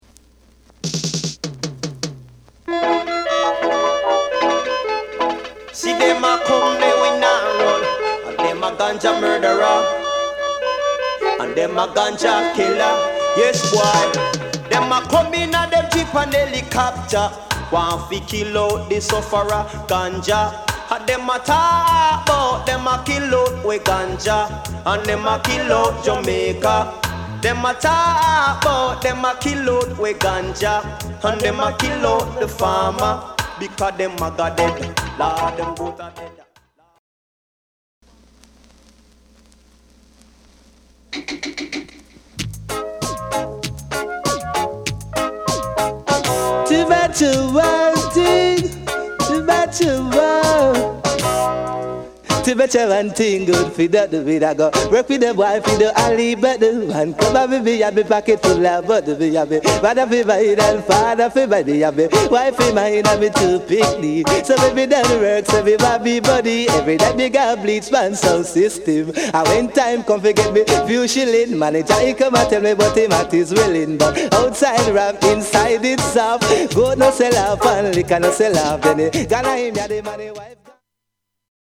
DANCEHALL REGGAE / DANCRHALL DJ